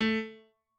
pianoadrib1_11.ogg